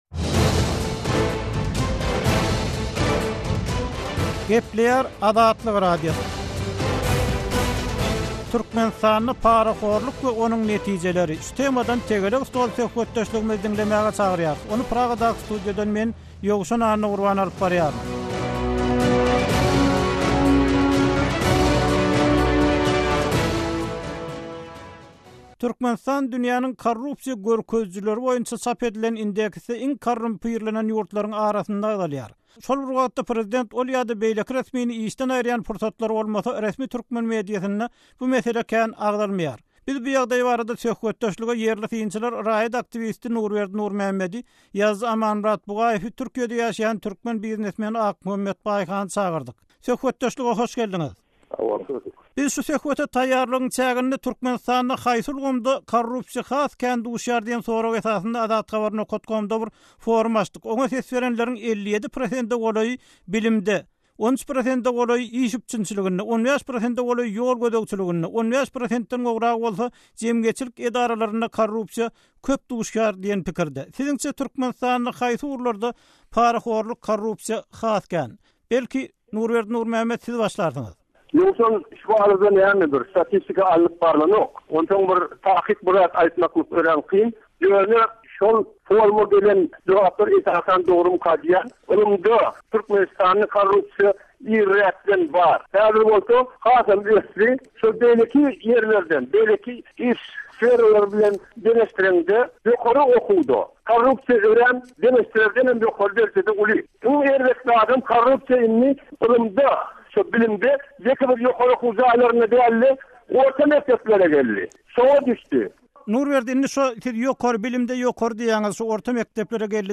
Azatlyk Radiosynyň nobatdaky "Tegelek stol" söhbetdeşligi şu meselä bagyşlandy. Oňa ýerli synçylar gatnaşýar.